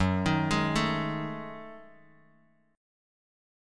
F#m6add9 chord